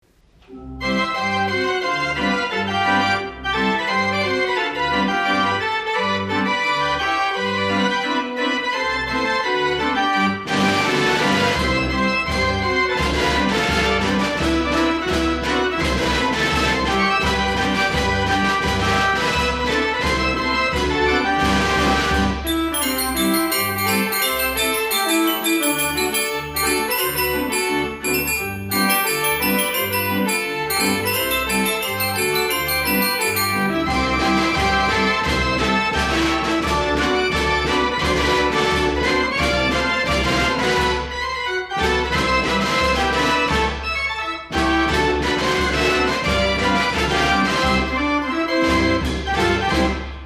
Accompaniment:-9 Stopped Flute Pipes; 18 Violin Pipes.
Traps:- Bass Drum, Snare Drum, and Cymbal.